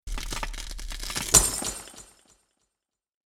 MIRROR_SHATTER.mp3